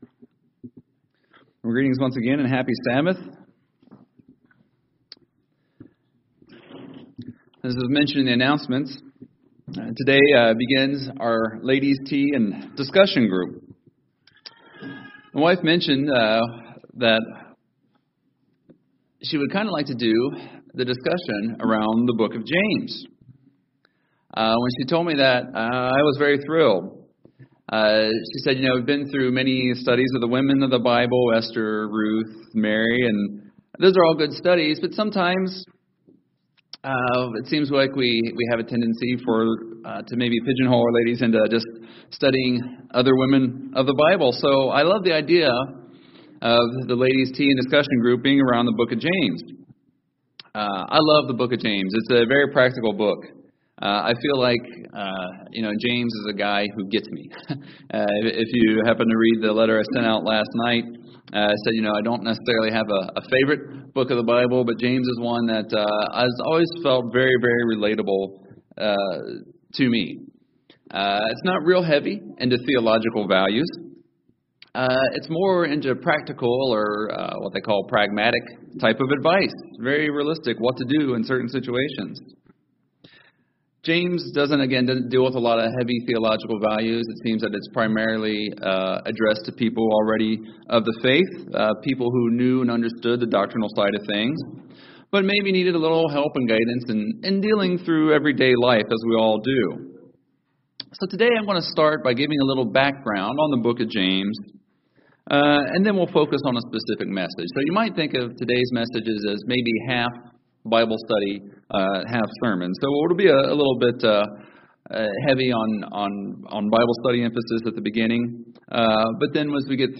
Sermons
Given in Charlotte, NC